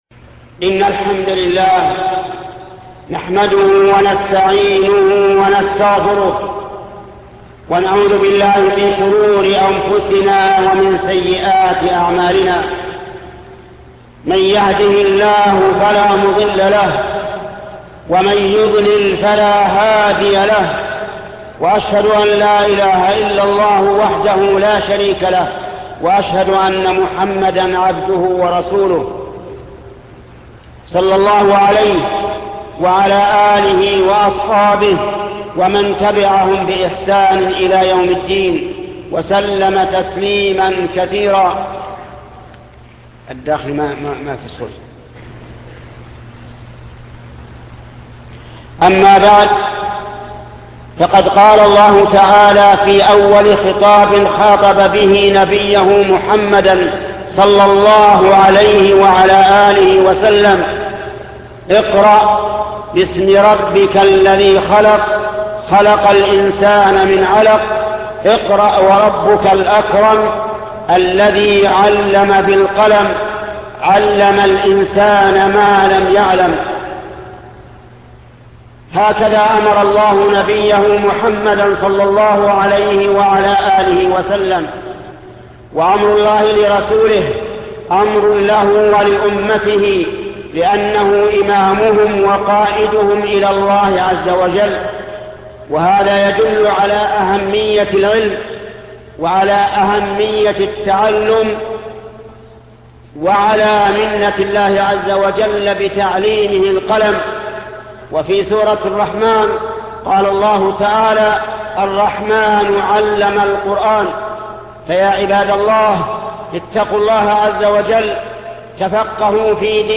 خطبة حكم تهنئة الكفار ـ حقائق حول خسوف القمر وكسوف الشمس الشيخ محمد بن صالح العثيمين